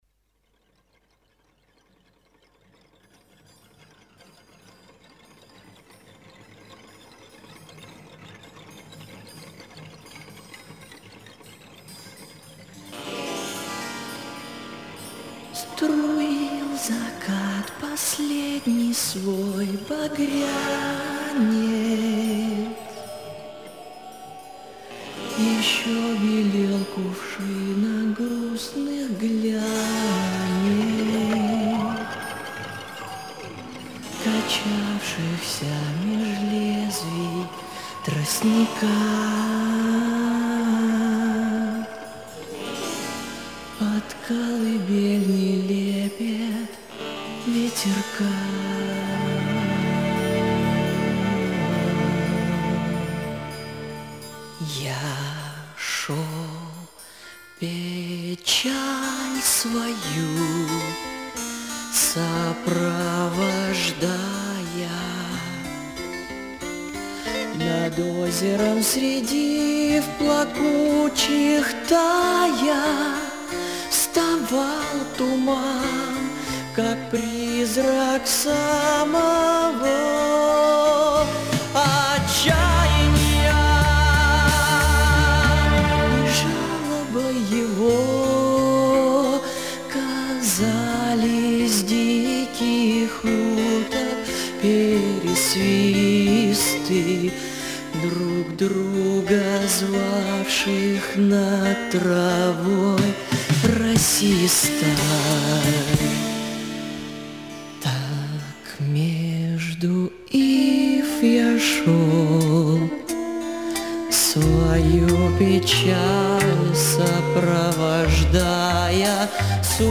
он выпускает концептуальный альбом в стиле арт-рок.